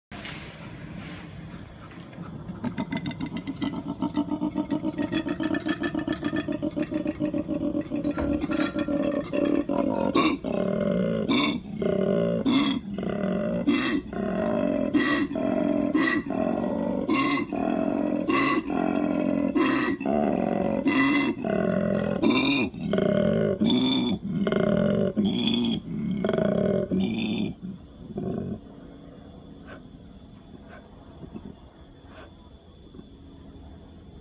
Звуки коалы
Громкий рёв коалы в сезон спаривания